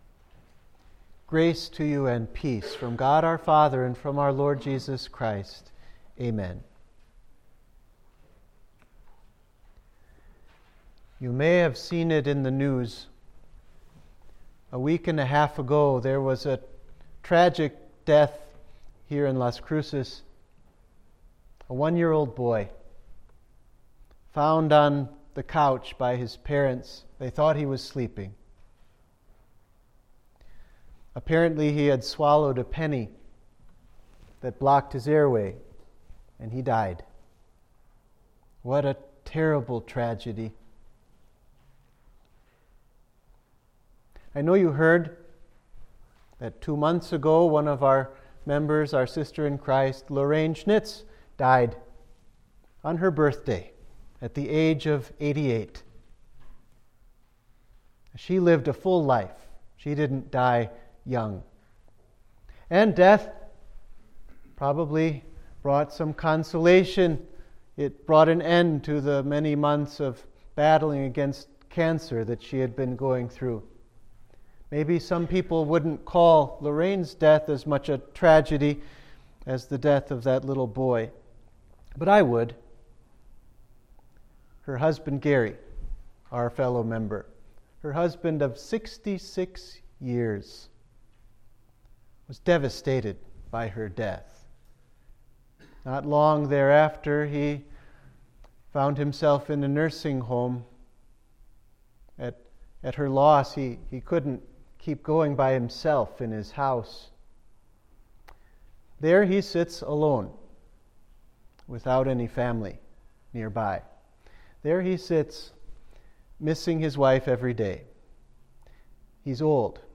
Sermon for Trinity 16